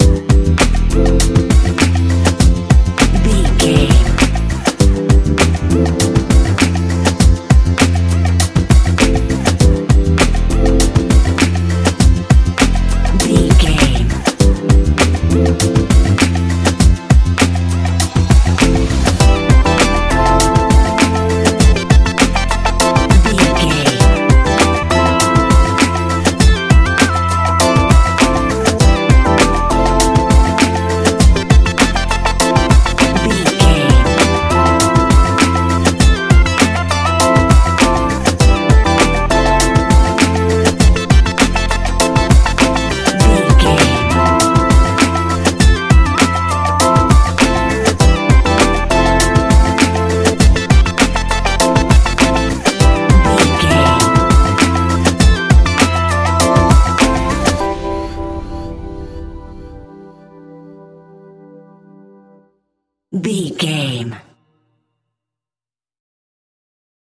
Ionian/Major
Lounge
sparse
new age
chilled electronica
ambient